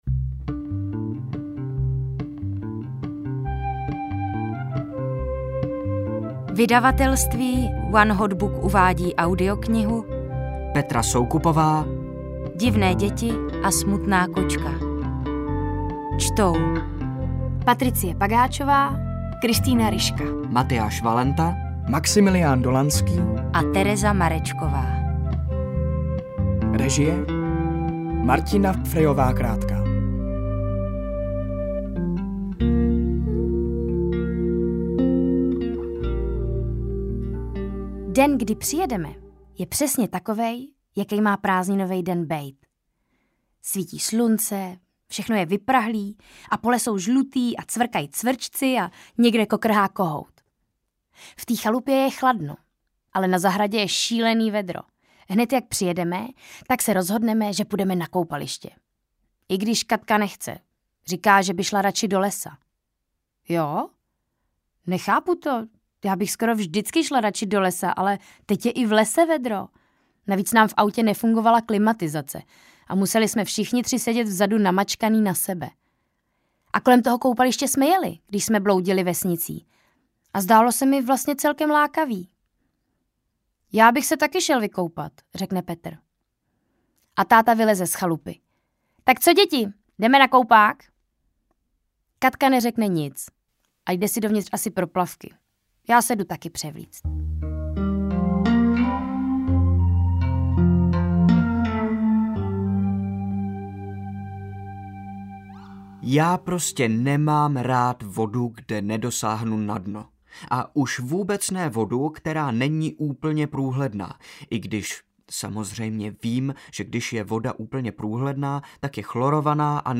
Divné děti a smutná kočka audiokniha
Ukázka z knihy